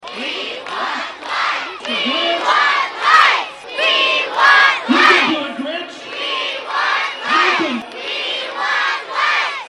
Manhattan’s Blue Earth Plaza was a festive scene Friday night.
That was the sound of the crowd demanding the Grinch restore the lights at the Blue Earth Plaza for the Festival of Lights.
we-want-lights-2.mp3